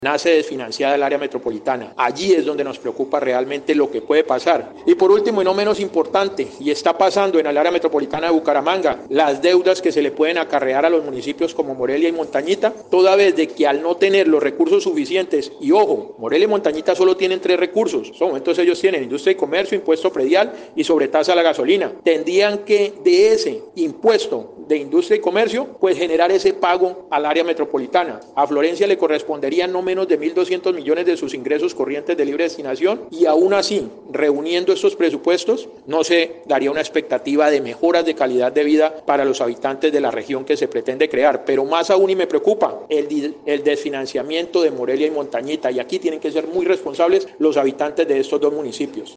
CONCEJAL_YOVANI_VASQUEZ_NO_-_copia.mp3